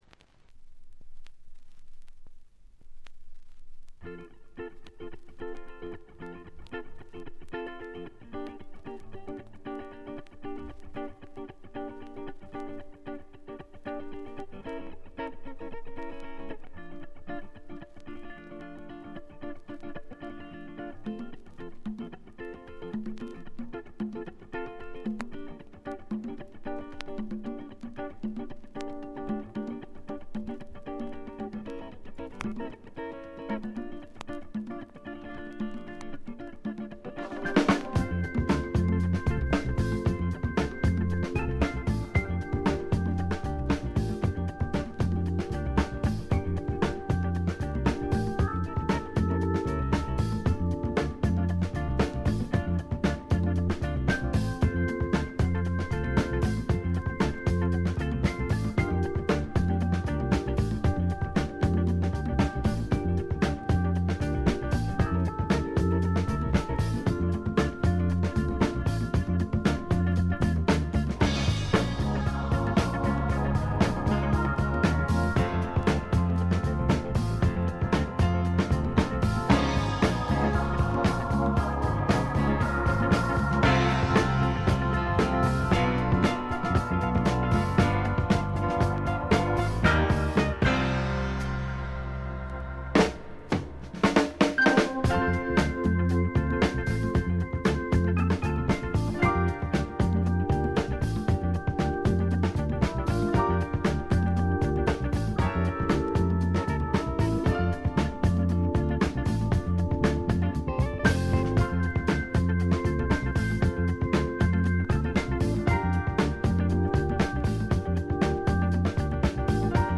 B1冒頭でちょっと目立つチリプチ。
フォーキーなアコースティック・グルーヴが胸を打つ名盤。
試聴曲は現品からの取り込み音源です。